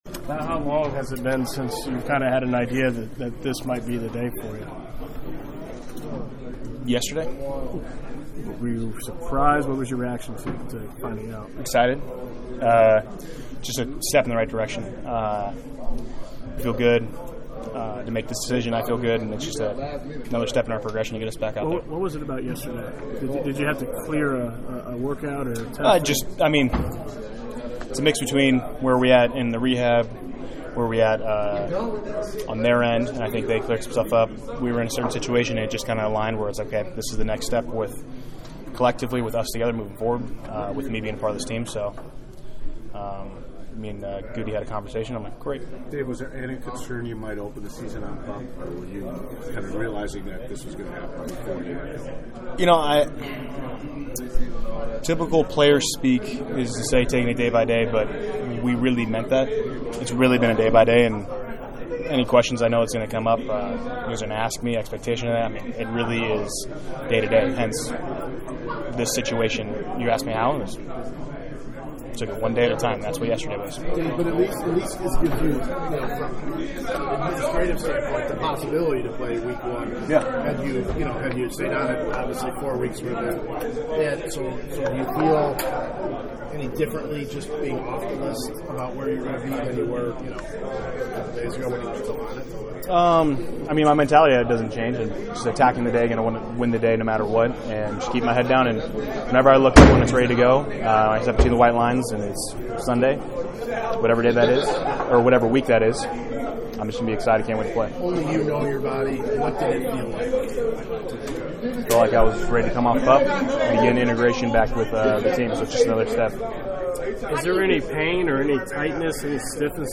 After practice, as you’d expect, Bakhtiari was front and center before a host of microphones and cameras to talk about his long awaited return from the lonely rehabilitation group to the full team practice.